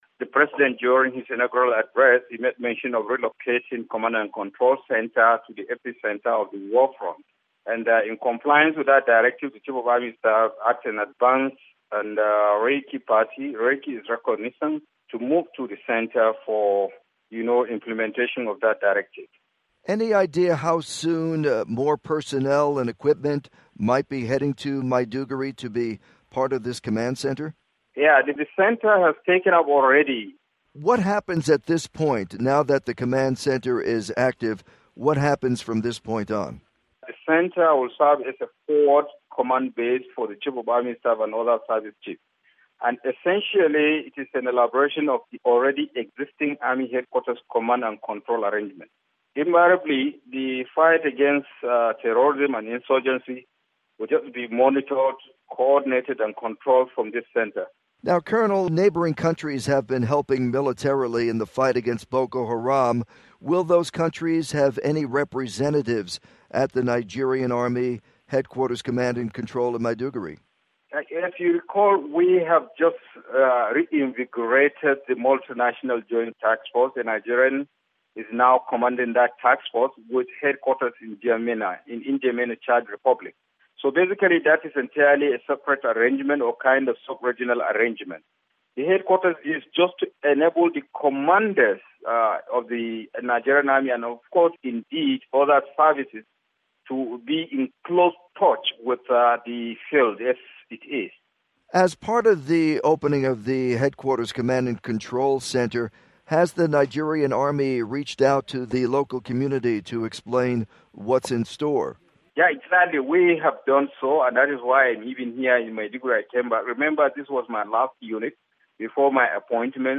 report on Nigerian army